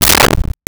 Close Cabinet Door 01
Close Cabinet Door 01.wav